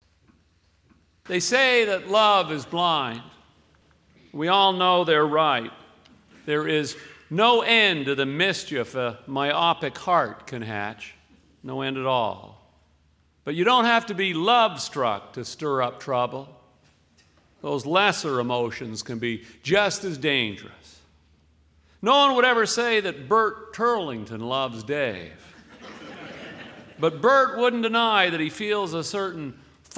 From Stuart McLean's 2010 release called Out and About, here's the single story called "The Cruise."